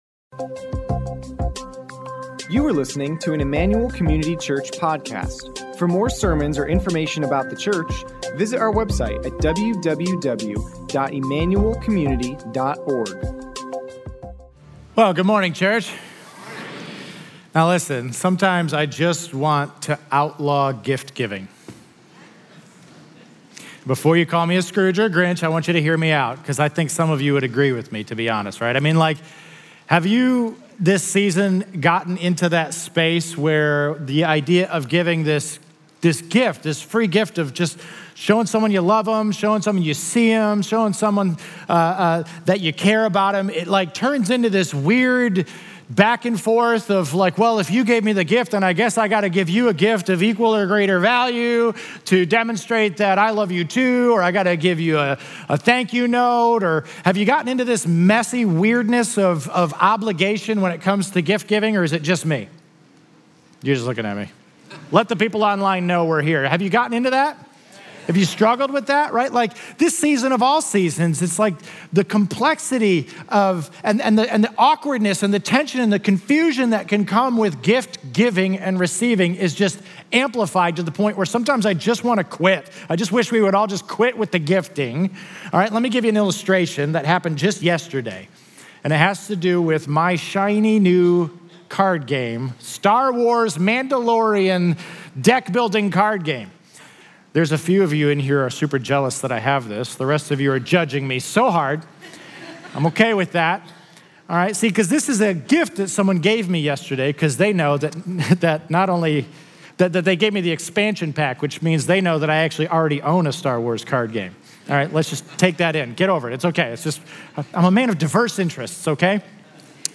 Emmanuel Community Church Sermon Podcast